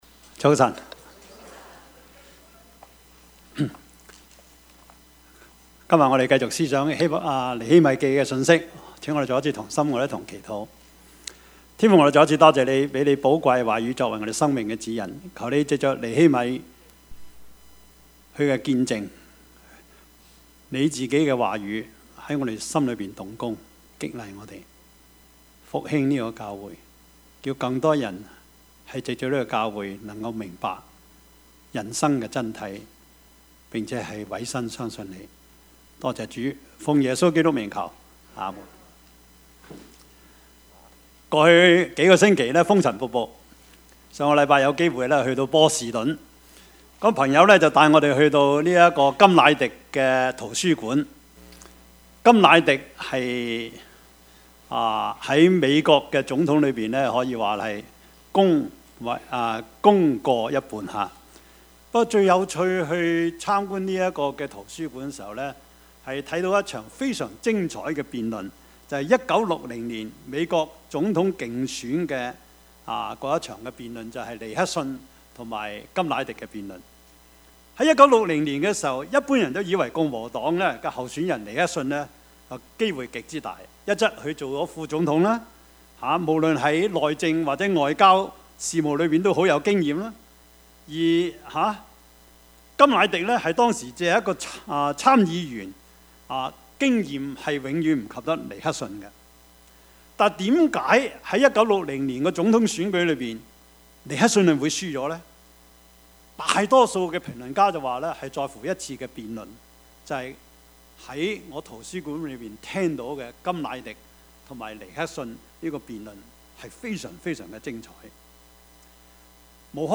Service Type: 主日崇拜
Topics: 主日證道 « 基督教倫理學(一) 矛盾人生 »